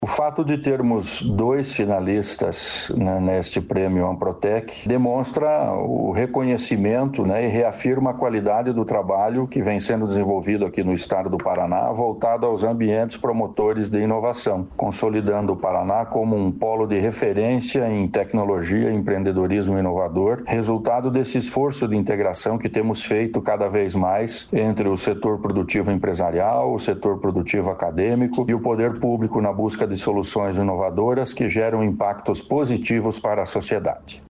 Sonora do secretário estadual da Ciência, Tecnologia e Ensino Superior do Paraná, Aldo Nelson Bona, sobre ecossistemas de inovação entre os melhores do Brasil